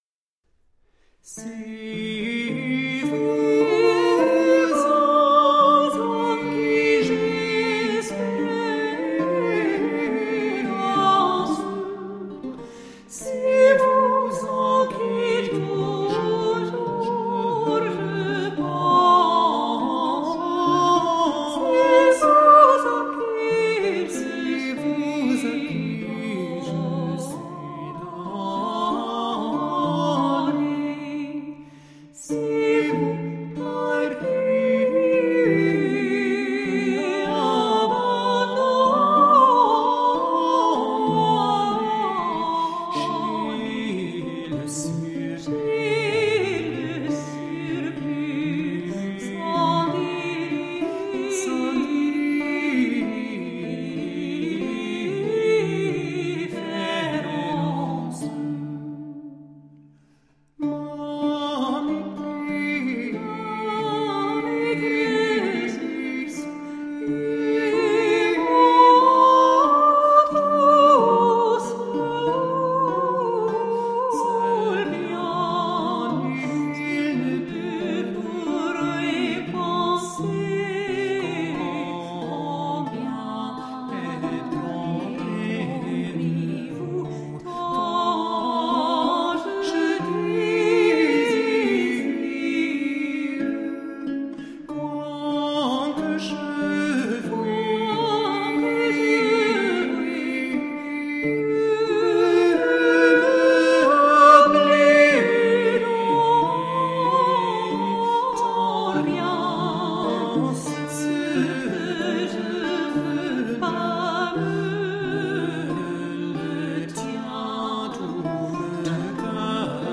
Late-medieval vocal and instrumental music.